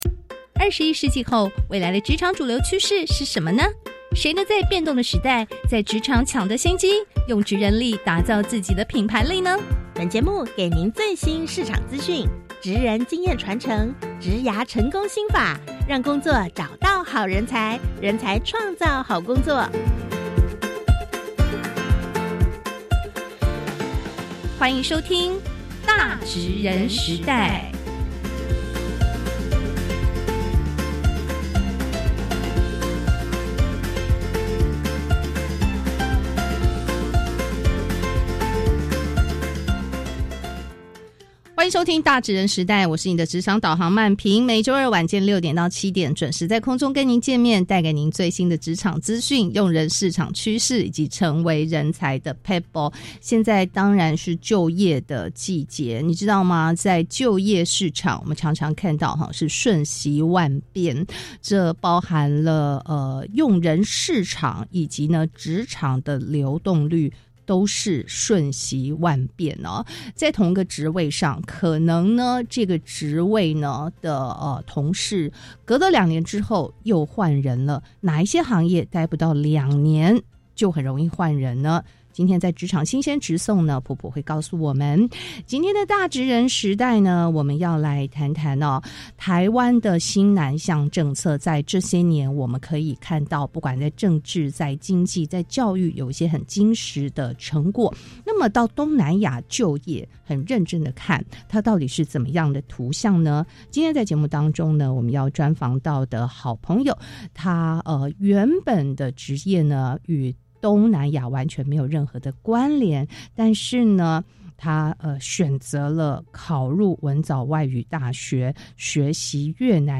放鬆聽》【各大廣播電台】~文藻師生專訪